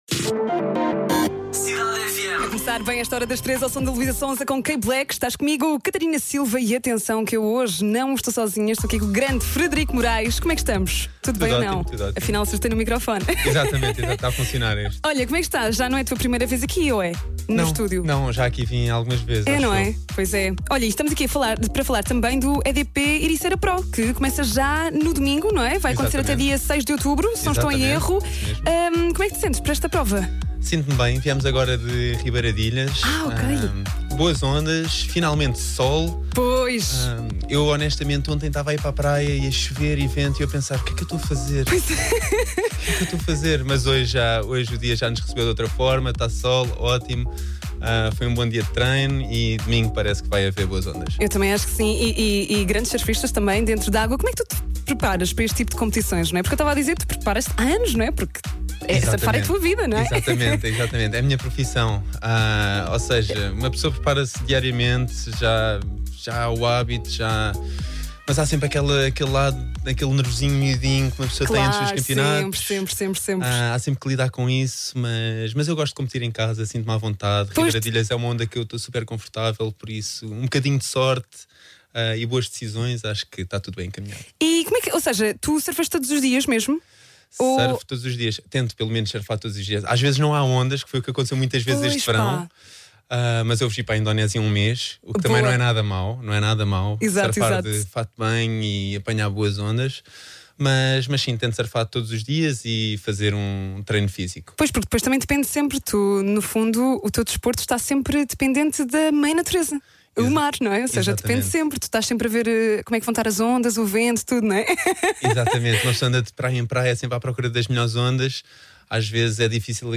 O EDP Ericeira PRO está quase a começar. O Frederico Morais esteve na Cidade FM para falar um pouco sobre este evento.